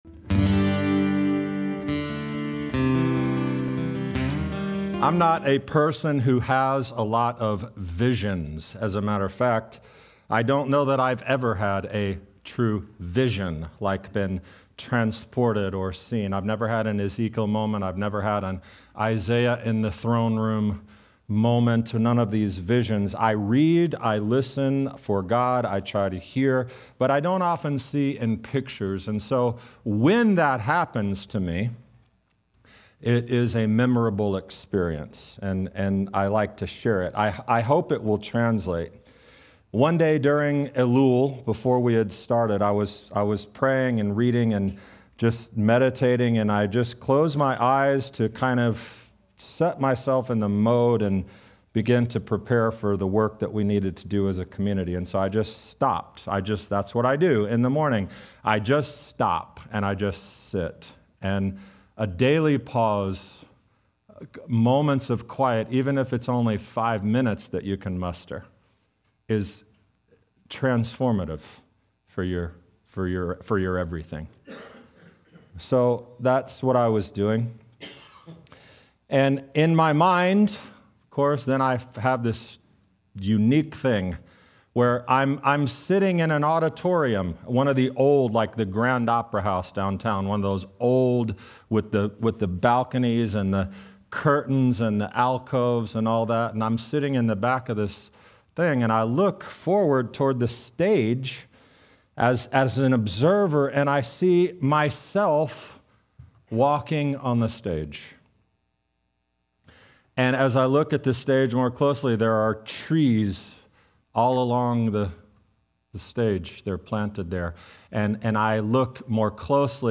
Join me for this timely and transformative teaching.